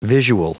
Prononciation du mot visual en anglais (fichier audio)
Prononciation du mot : visual